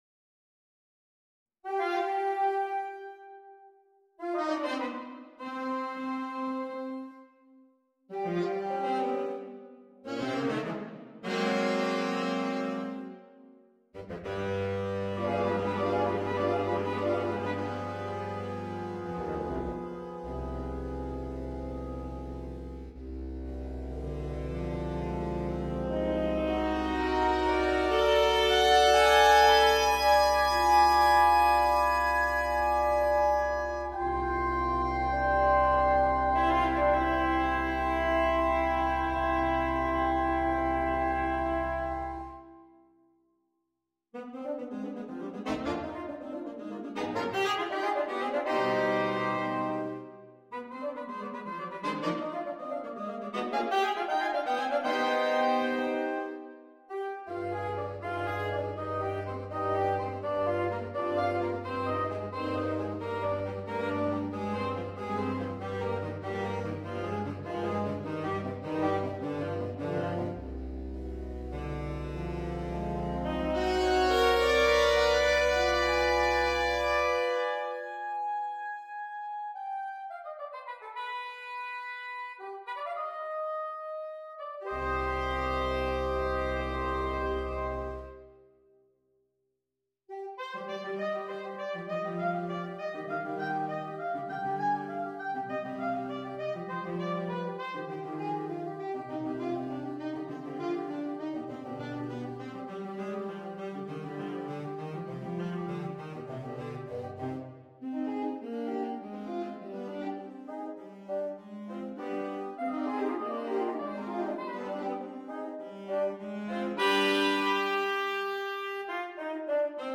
Аранжировка
для квинтета саксофонов.